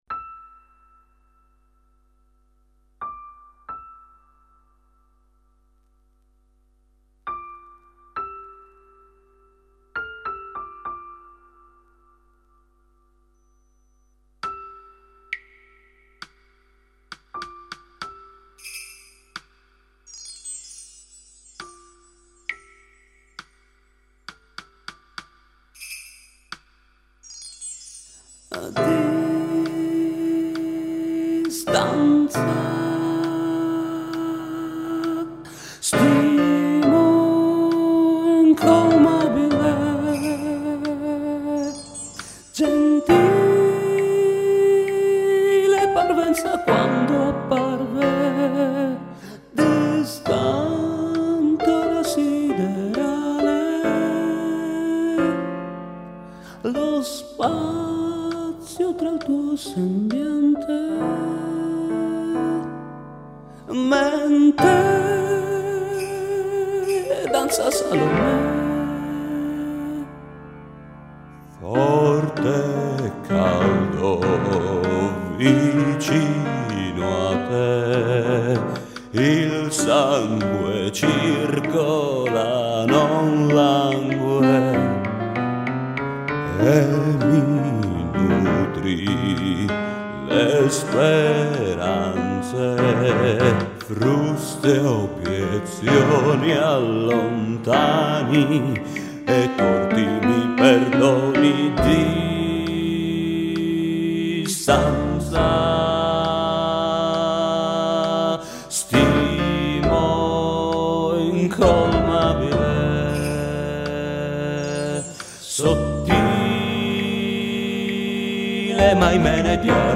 PROVINI di canzoni (registrazioni casalinghe)